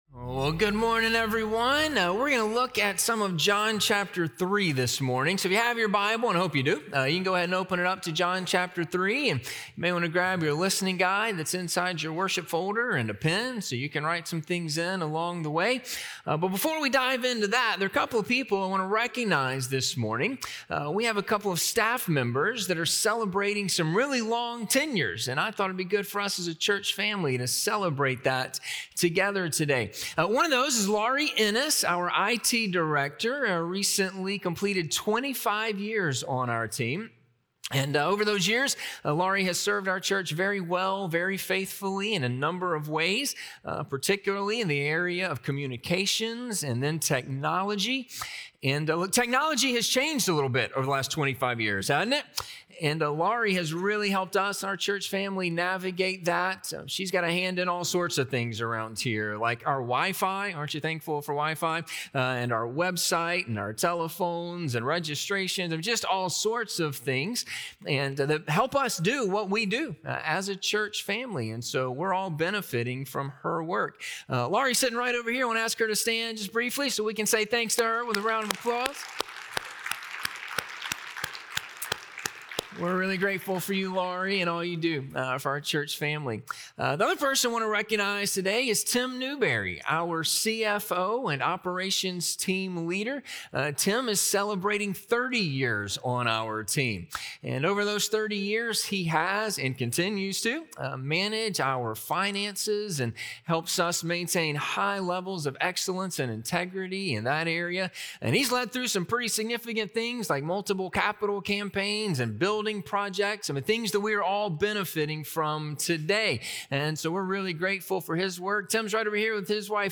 For God So Loved the World - Sermon - Ingleside Baptist Church